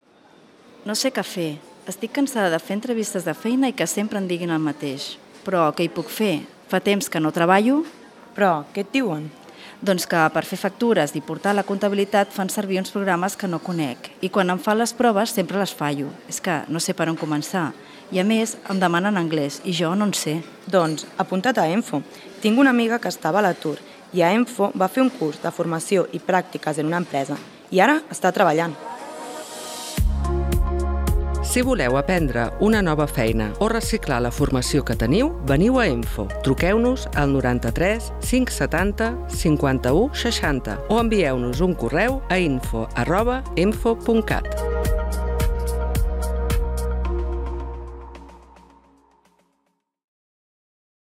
La setmana passada vam gravar dues noves falques de ràdio d’EMFO a Ràdio Mollet per fer difusió dels programes adreçats a joves i de la formació ocupacional per a persones en situació d’atur.